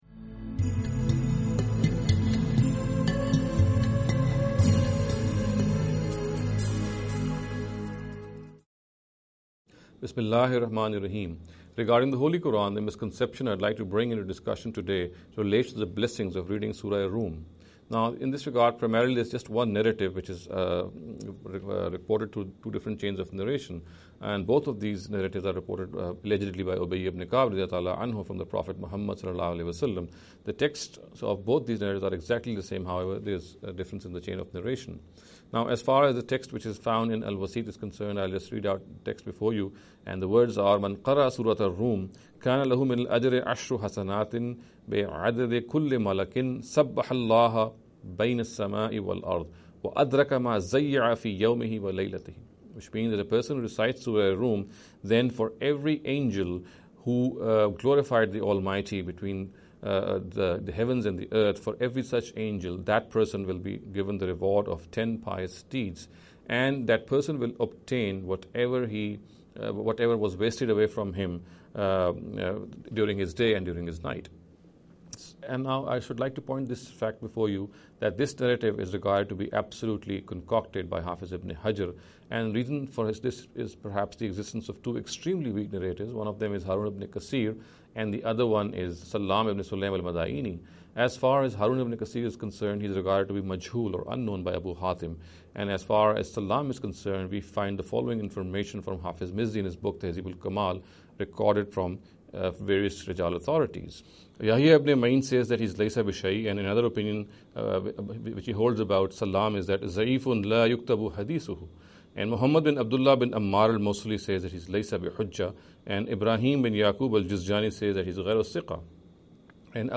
This lecture series will deal with some misconception regarding the Holy Quran. In every lecture he will be dealing with a question in a short and very concise manner.